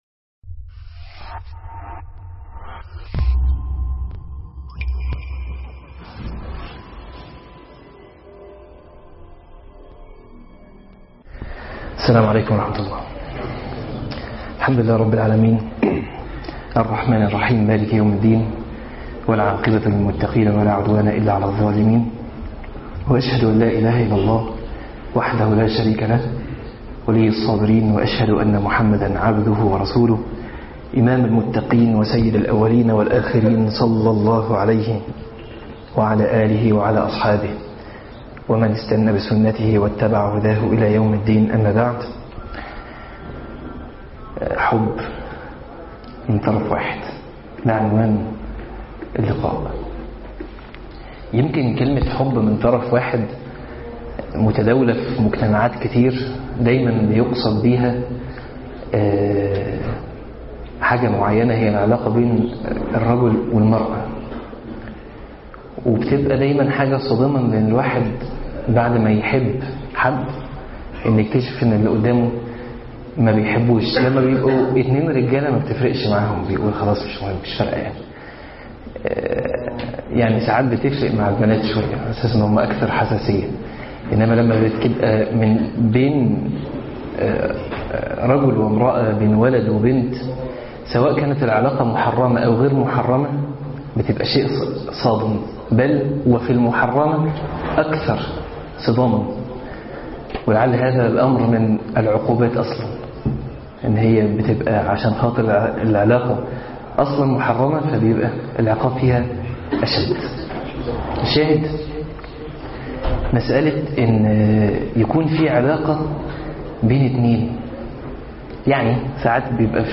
حب من طرف واحد- من دروس المساجد